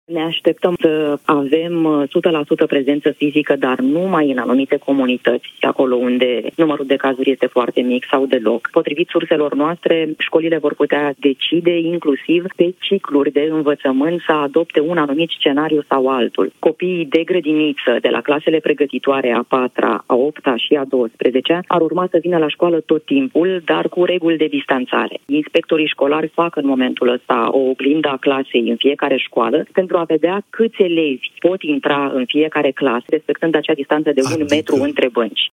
a spus, în emisiunea Deșteptarea de Vară